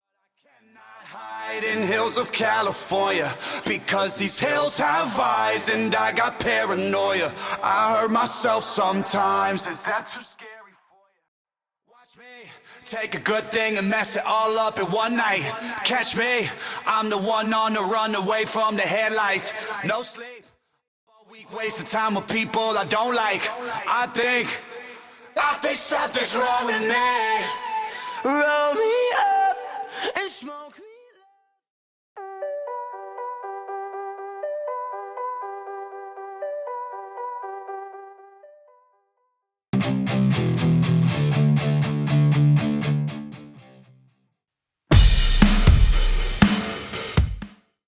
Studio All Bassline Stem
Studio Electric & Acoustic Guitars Stem
Studio Keys & Strings Stem
Studio Percussion & Drums Stem